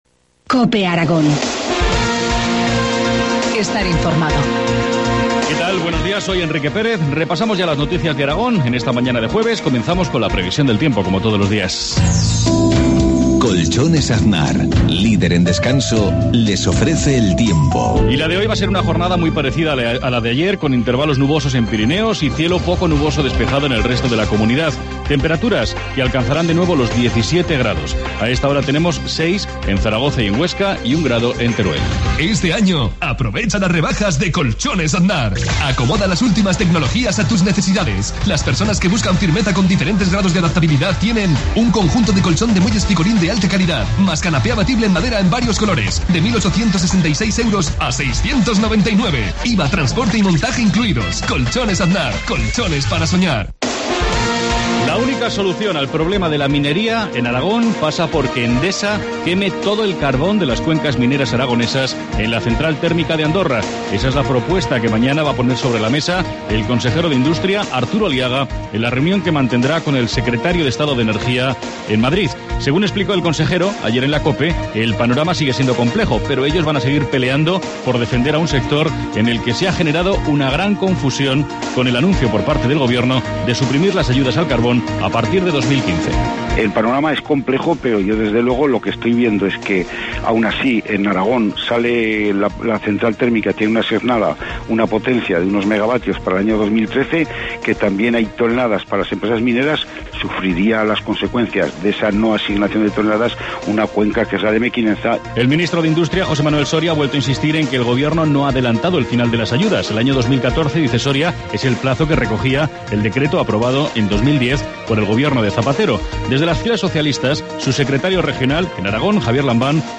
Informativo matinal, jueves 31 de enero, 7.53 horas